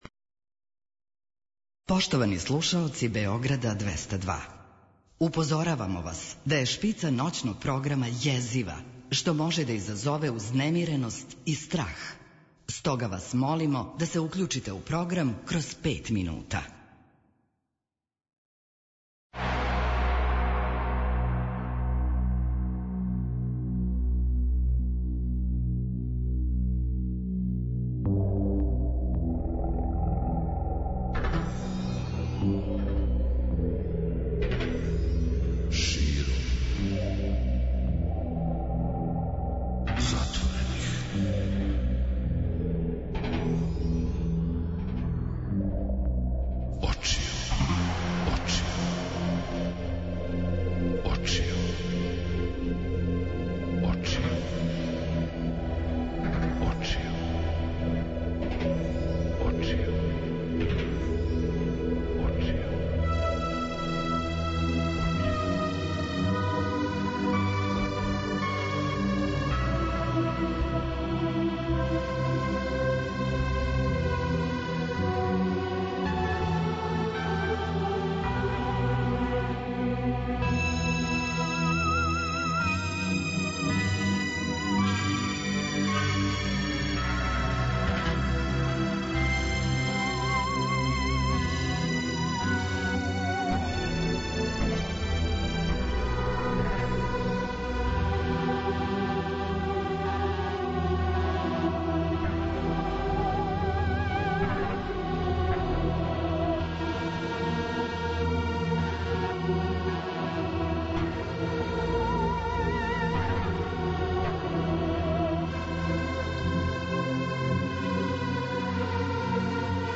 Гост Ноћног програма Широм затворених очију је Ирфан Менсур, глумац, редитељ, сценариста и педагог.